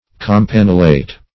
Campanulate \Cam*pan"u*late\, a. (Bot.)